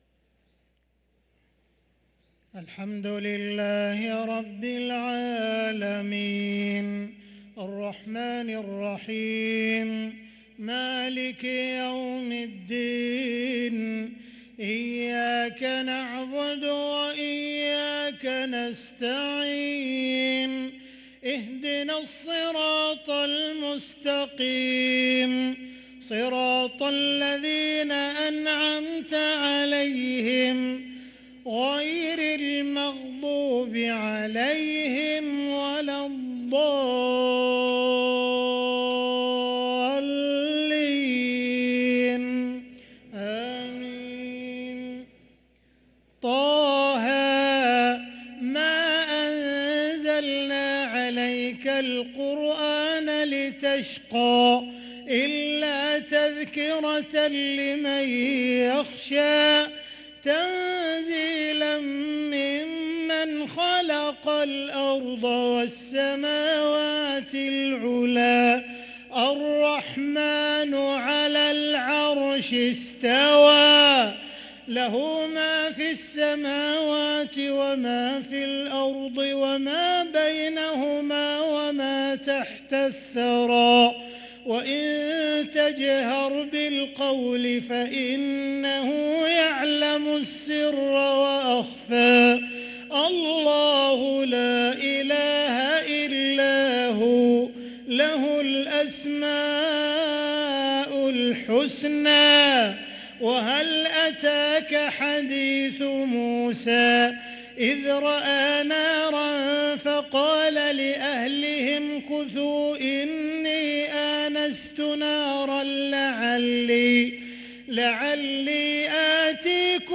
صلاة العشاء للقارئ عبدالرحمن السديس 12 رجب 1445 هـ
تِلَاوَات الْحَرَمَيْن .